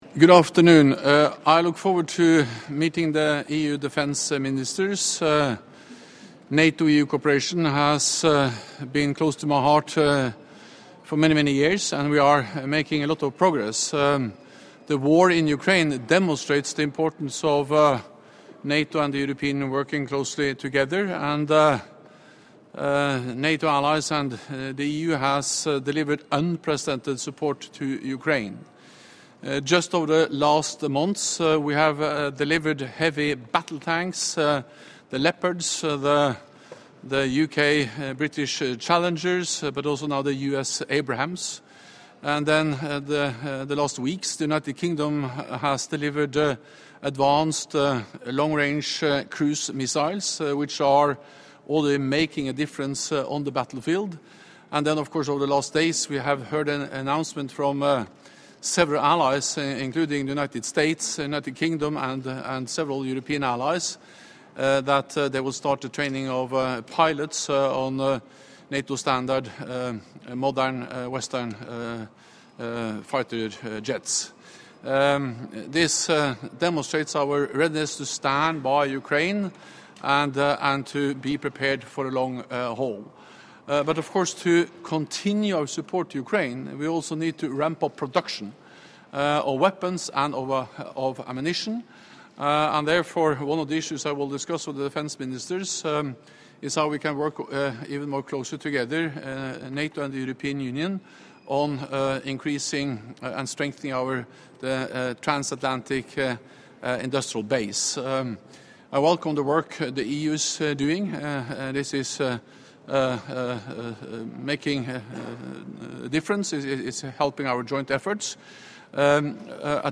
Doorstep statement
by NATO Secretary General Jens Stoltenberg ahead of the meeting of the Foreign Affairs Council of the European Union with Defence Ministers